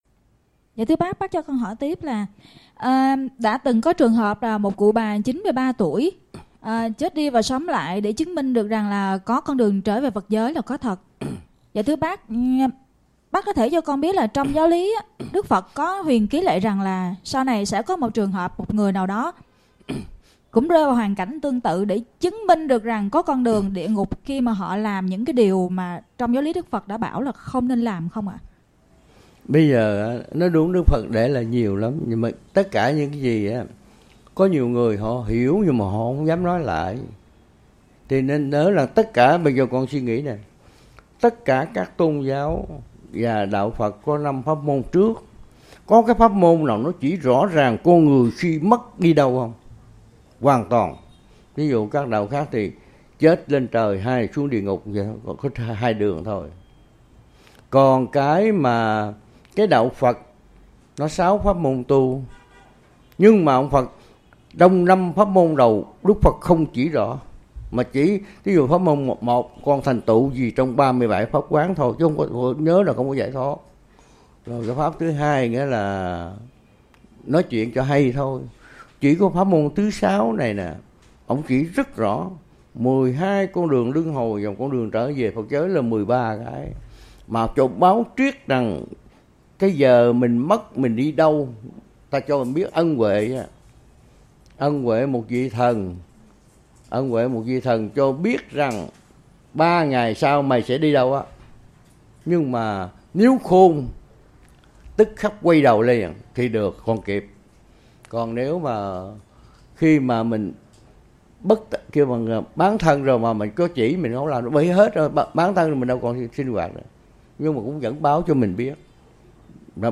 Trò hỏi:
Thầy trả lời